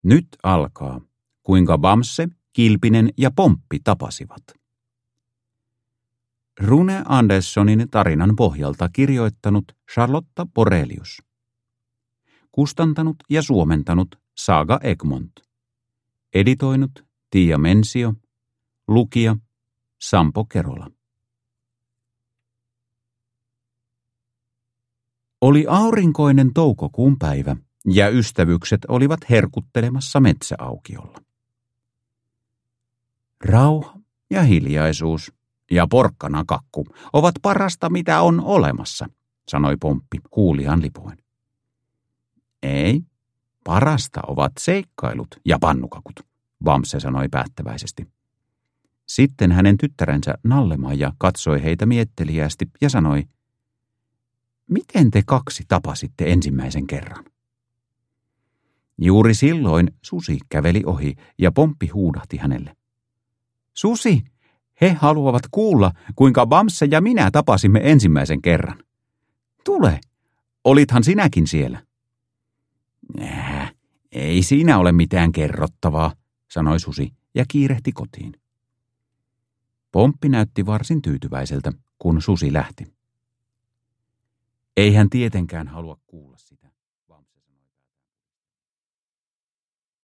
Kuinka Bamse, Kilpinen ja Pomppi tapasivat – Ljudbok